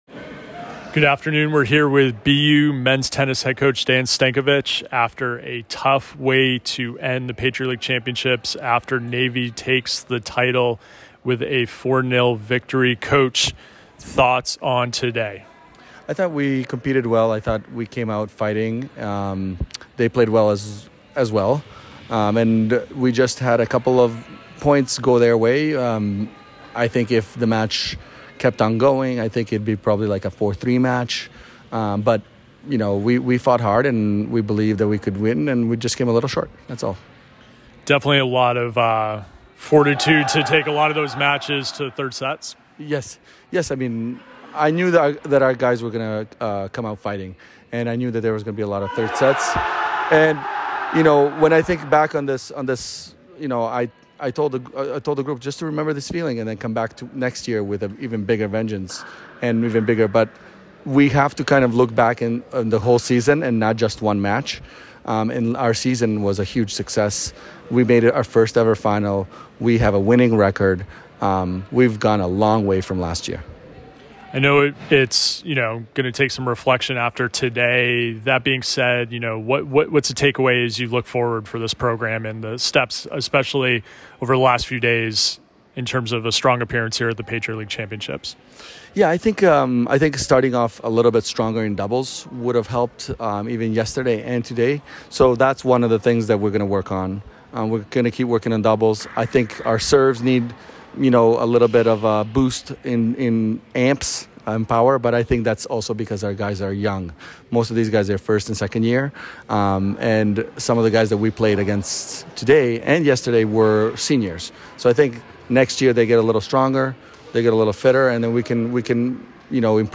Navy PL Final Postmatch Interview